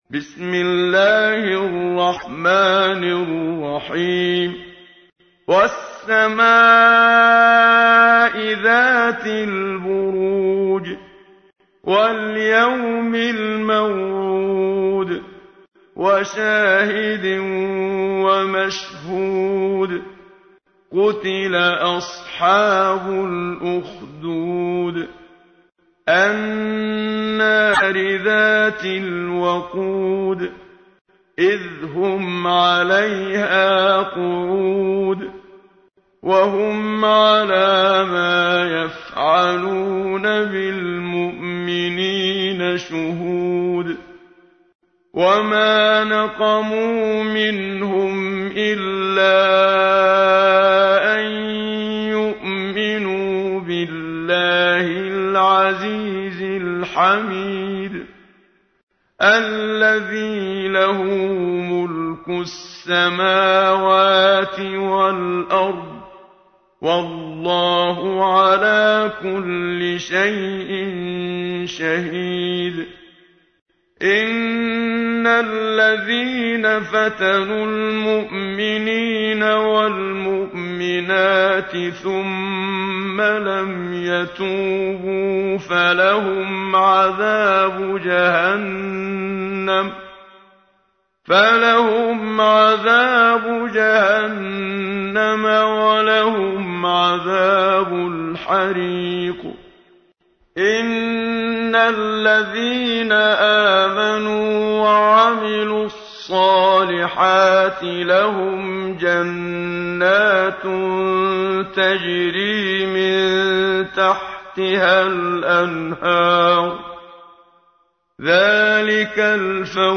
تحميل : 85. سورة البروج / القارئ محمد صديق المنشاوي / القرآن الكريم / موقع يا حسين